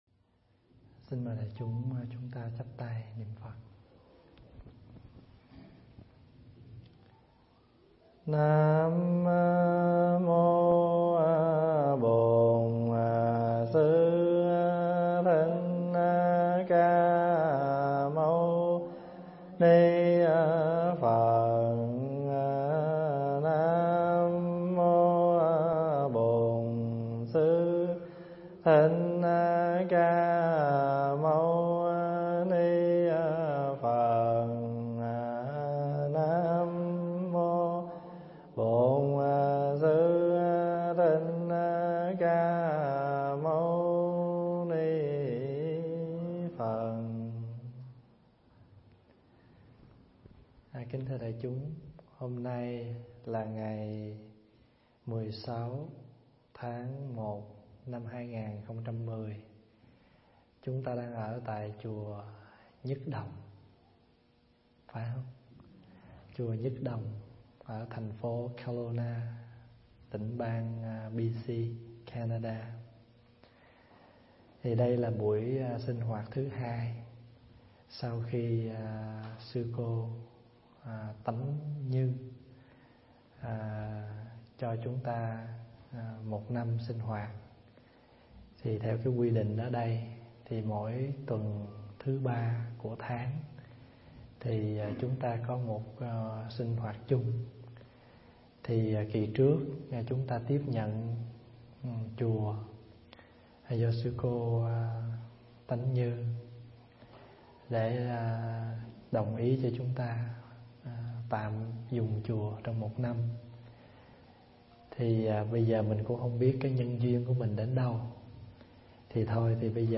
thuyết giảng tại Chùa Nhứt Đồng, Kclowna, Canada